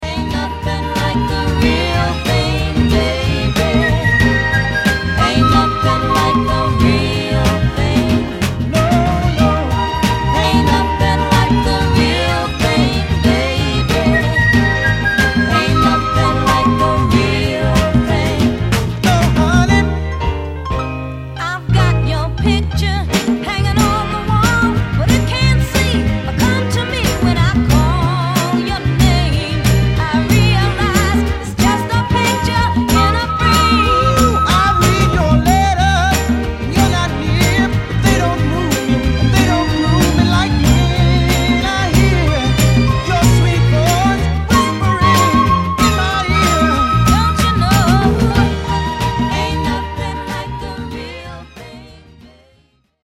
ici mon express-mix sur Logic